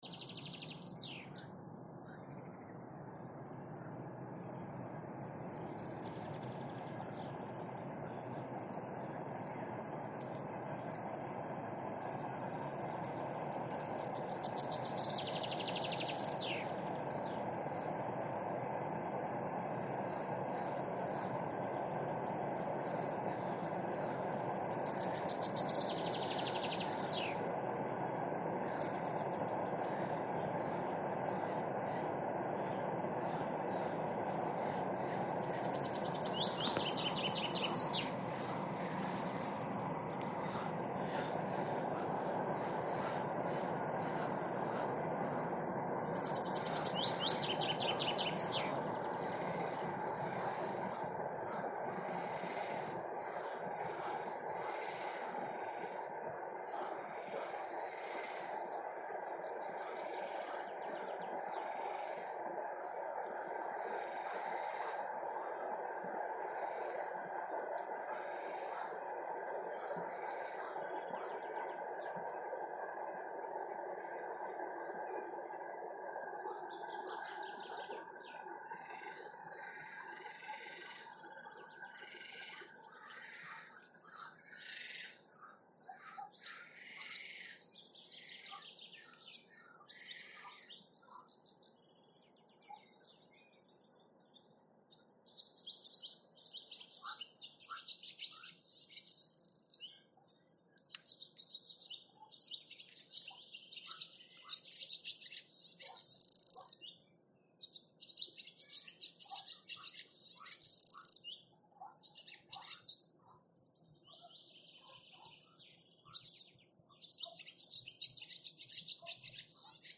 Пение птиц Природа